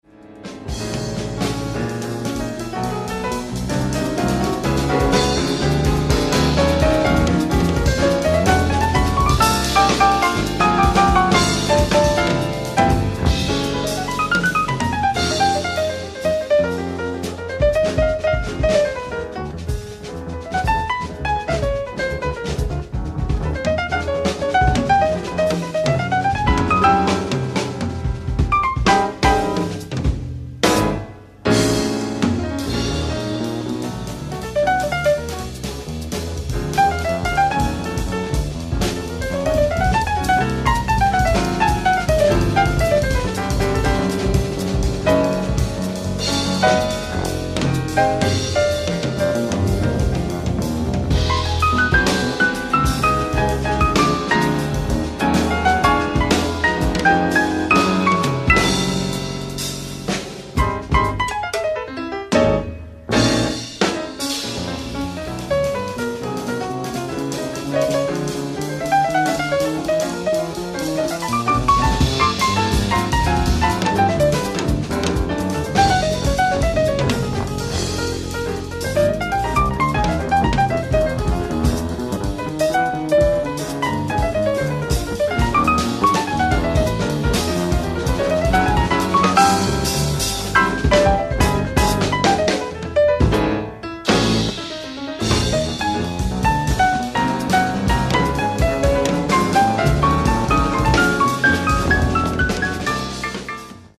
ライブ・アット・ファブリック、ハンブルグ、ドイツ 10/21/1987
※試聴用に実際より音質を落としています。